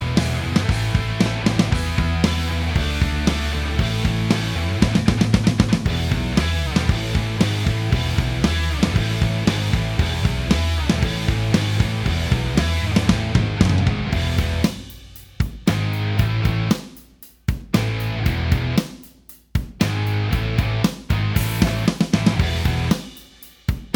Minus Main Guitar Indie / Alternative 3:30 Buy £1.50